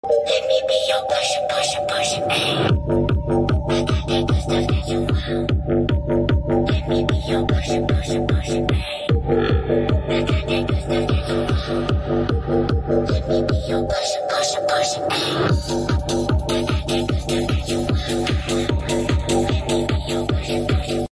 Yeahbox sonic boom MAX Three-way subwoofer